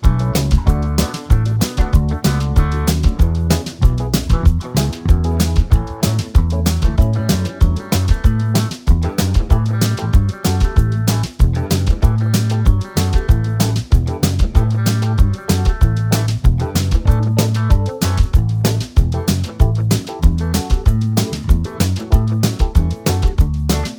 no Backing Vocals Soft Rock 3:37 Buy £1.50